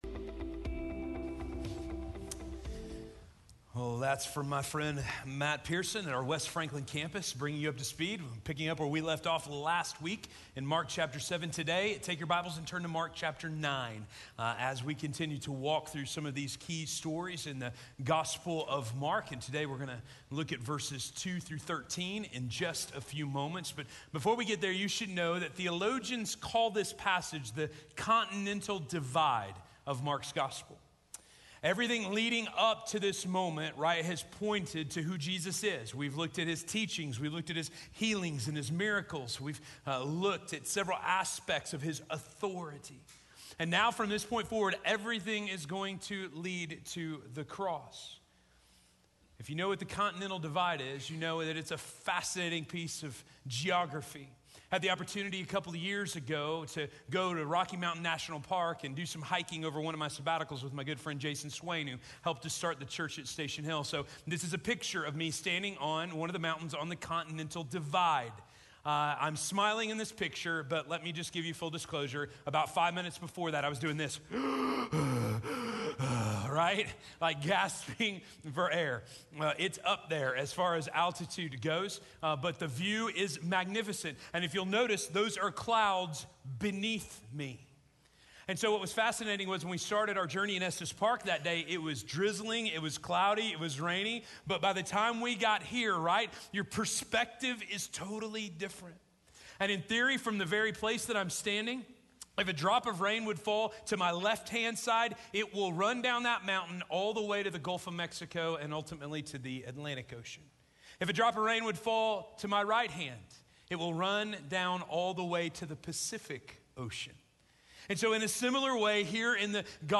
Transfiguration - Sermon - Station Hill